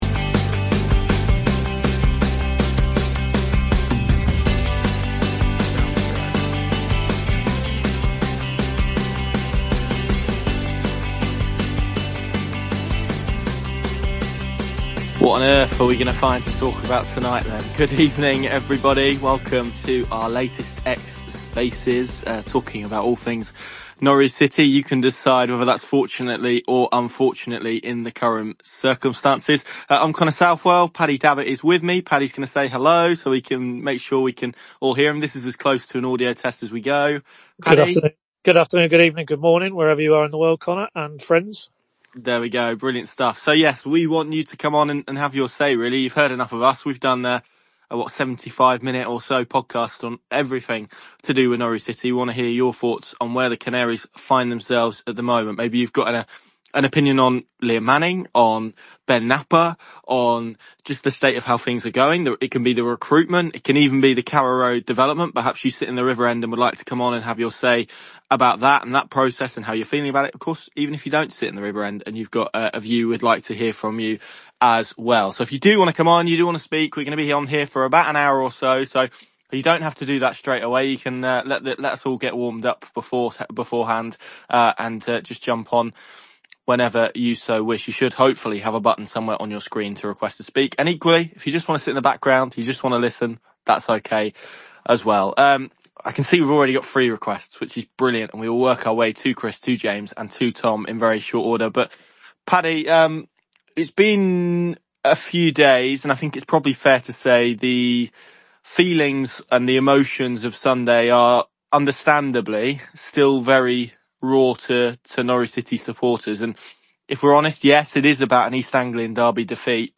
spoke to City fans via X Spaces on Wednesday evening (October 8) to get their opinions on the current events at Carrow Road.